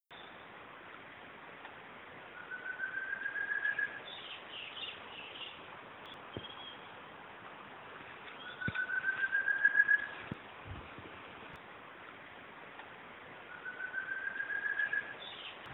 Hylopezus dives Thicket Antpitta Tororoi Pechicanelo (Hormiguero)
antpitathicket.wav